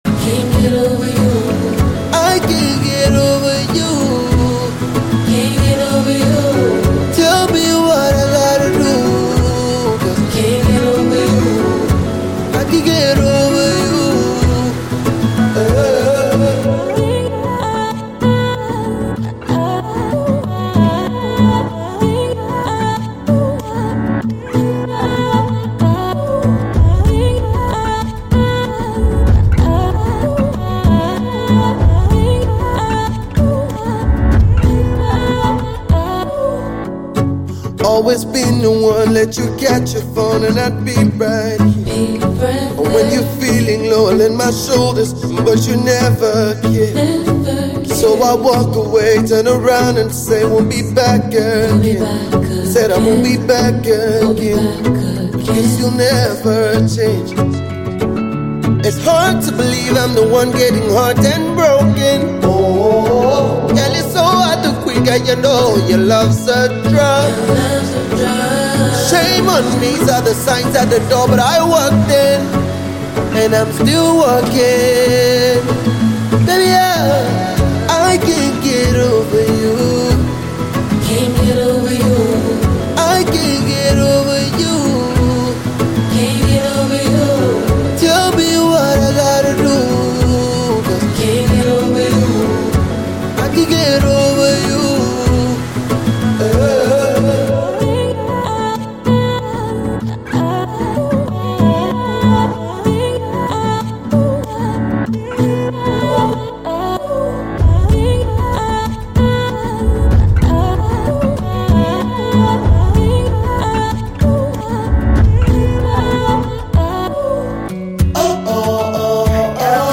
uber-talented singer
RnB